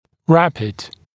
[‘ræpɪd][‘рэпид]быстрый, скорый; скоротечный